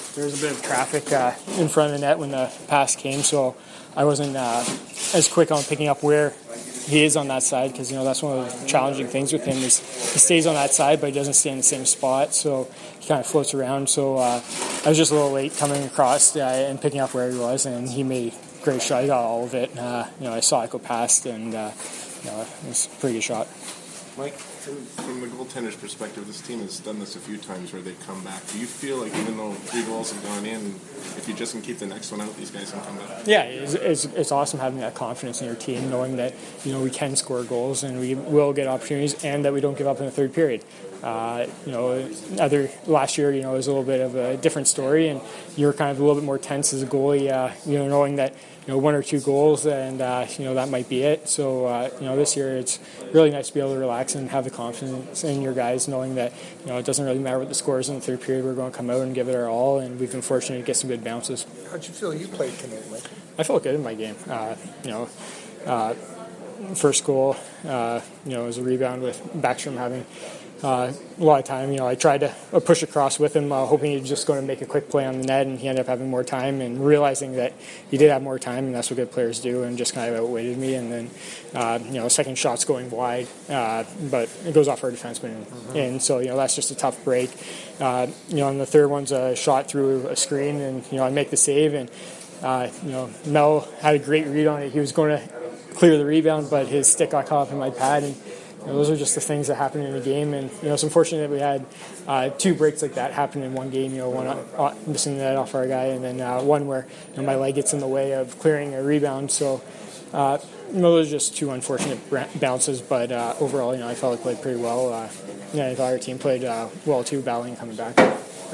Post-game from the Jets & Capitals dressing rooms as well as from both coaches.
Player post-game audio:
November-3-2016-Michael-Hutchinson-post-game.mp3